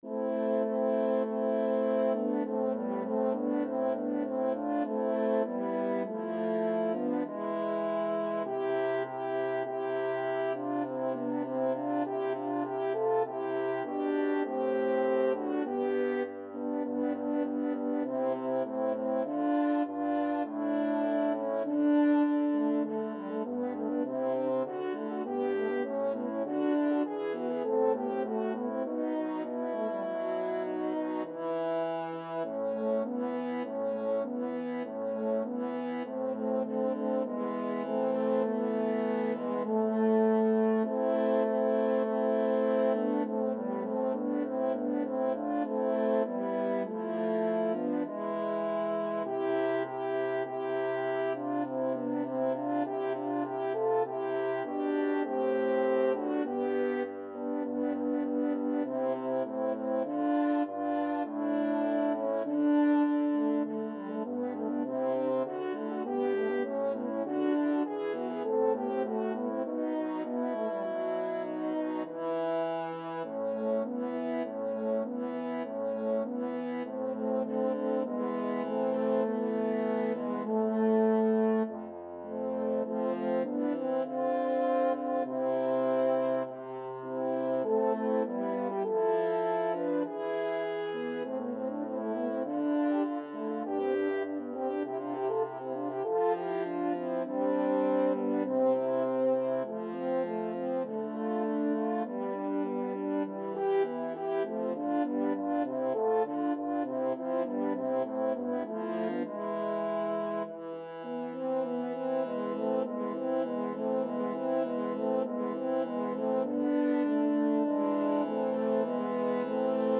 [Moderato]
4/4 (View more 4/4 Music)
Classical (View more Classical French Horn Trio Music)